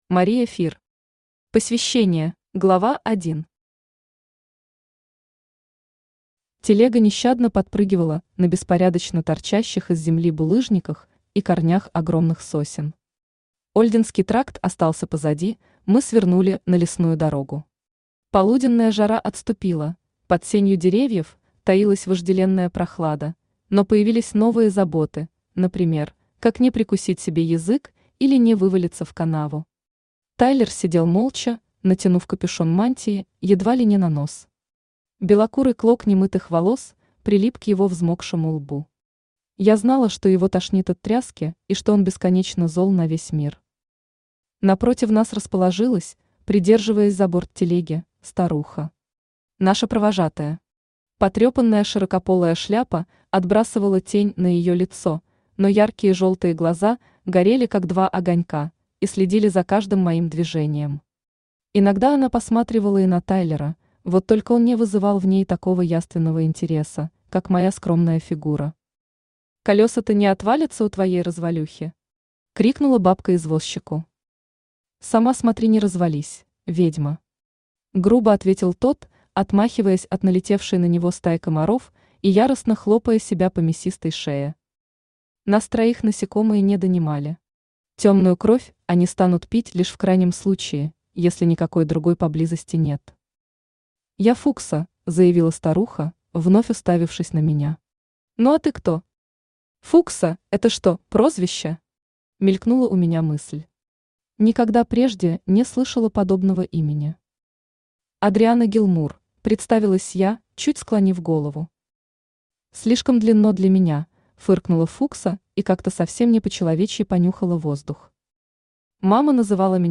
Аудиокнига Посвящение | Библиотека аудиокниг
Aудиокнига Посвящение Автор Мария Фир Читает аудиокнигу Авточтец ЛитРес.